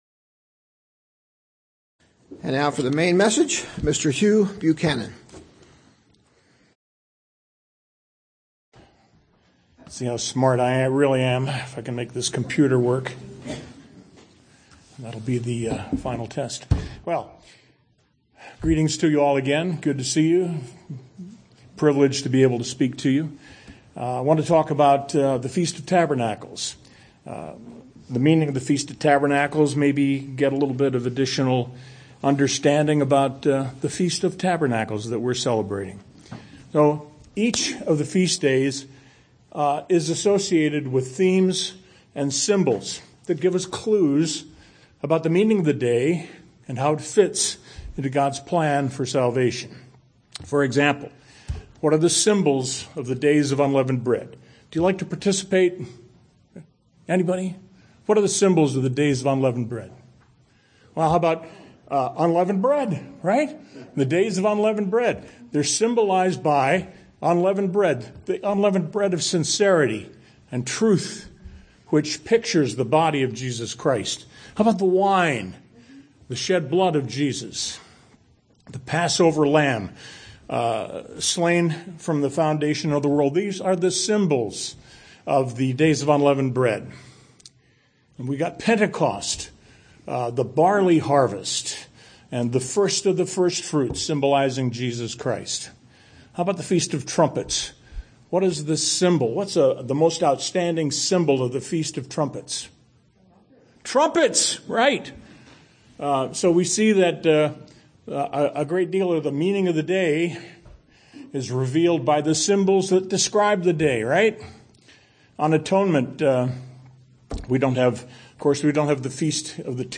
The purpose of this sermon is to discuss the meaning of Tabernacles.